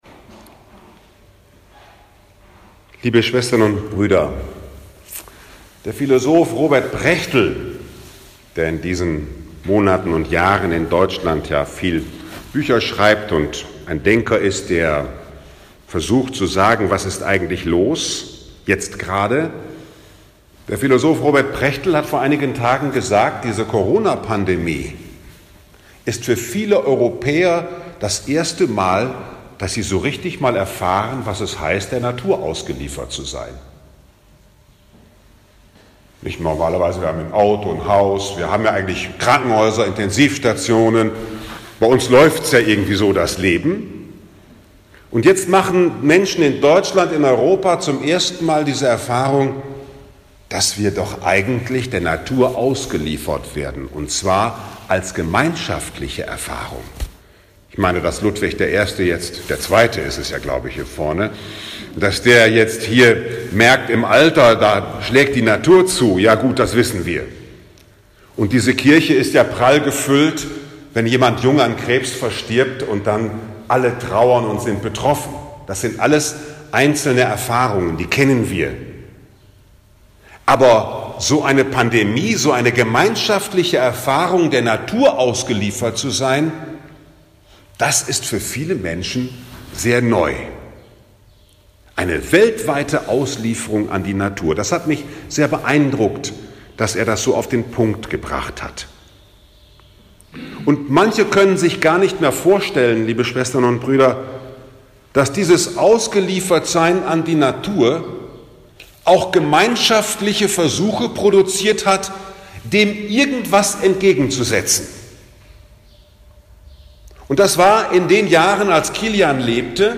Mission Weltsinn Vom christlichen Zeugnis, dass die Natur uns nicht ängstigen muss, weil ein Schöpfer in ihr wohnt 5. Juli 2020 10:00 Uhr Sankt Kilian, Mädelhofen, Kilianifestpredigt